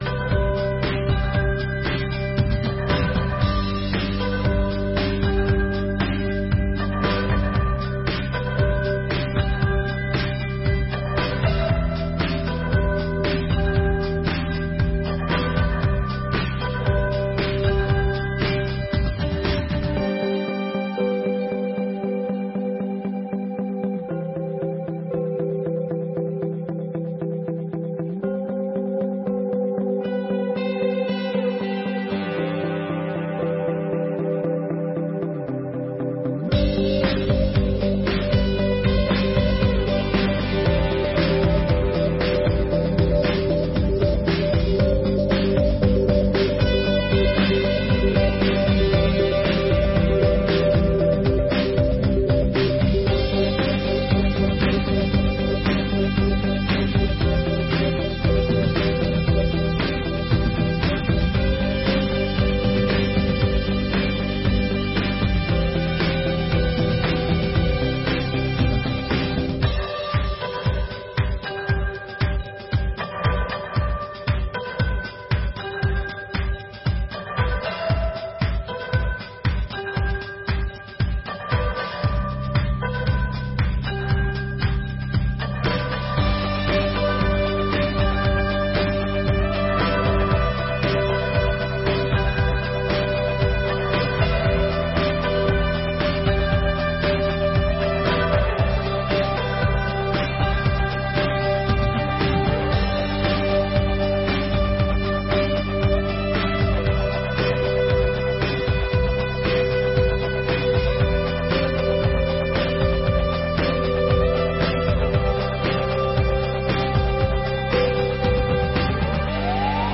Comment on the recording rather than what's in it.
36ª Sessão Ordinária de 2022